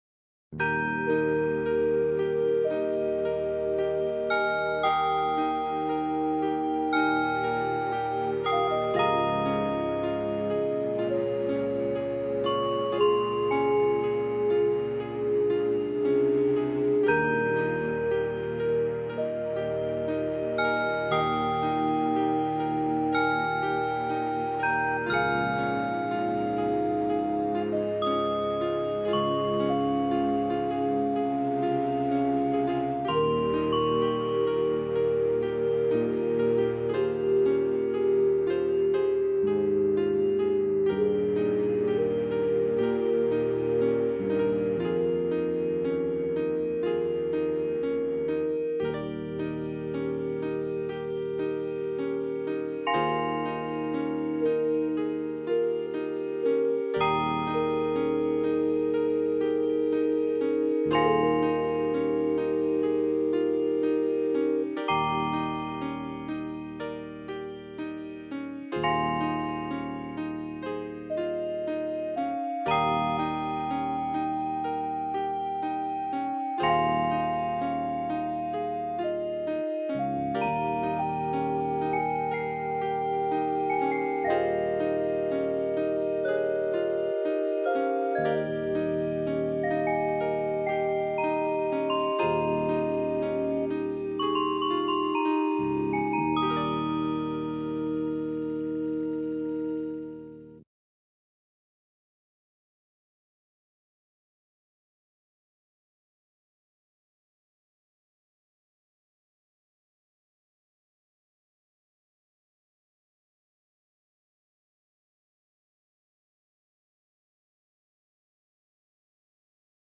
Nice sounding melody with crystal and oricana